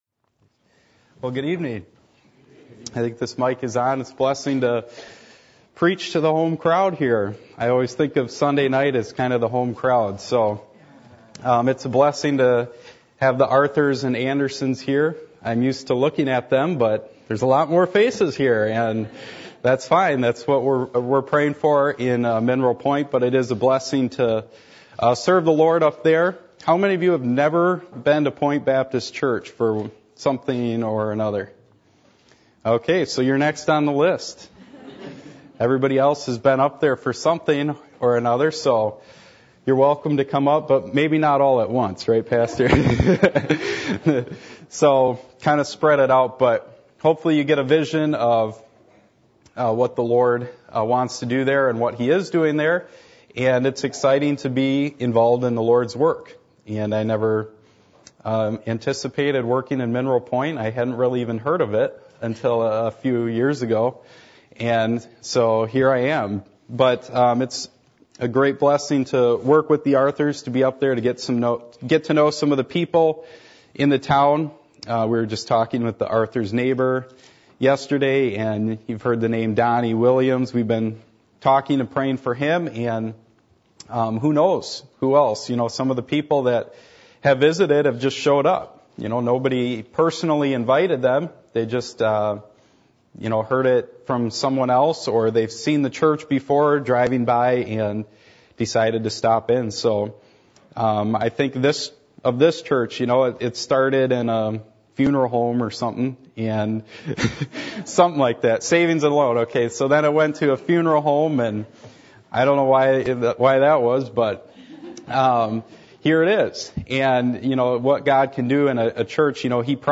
Passage: John 6:25-35 Service Type: Sunday Evening